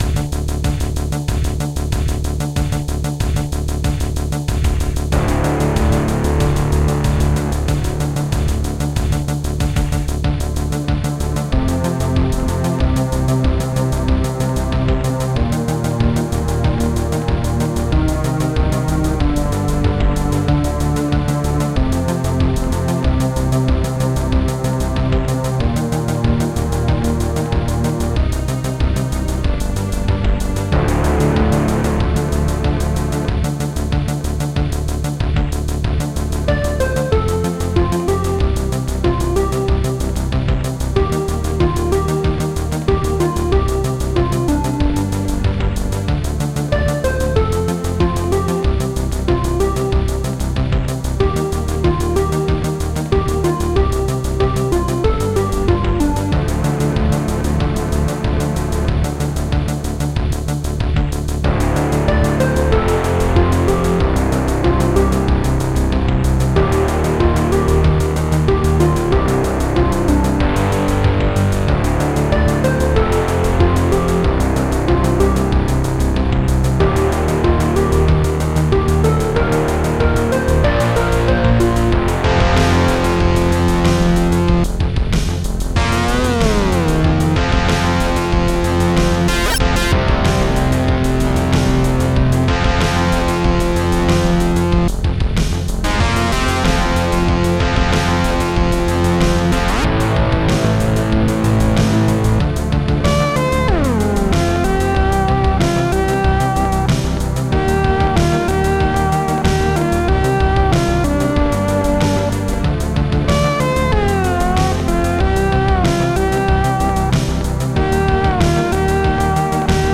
ST-03:metalchord
ST-01:RideCymbal
ST-02:bass
ST-05:Strings5
ST-04:DigiHarp
ST-01:akaisnare
ST-01:tom-2